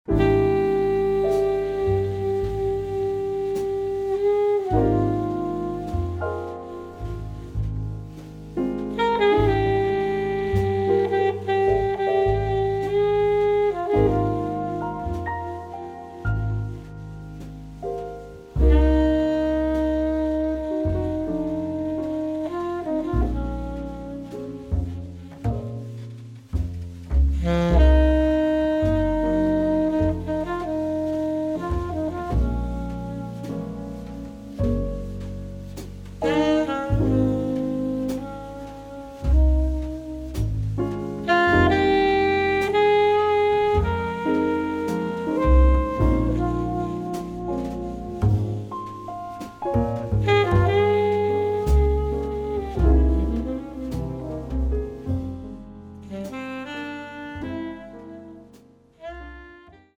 sax
piano
bass
drums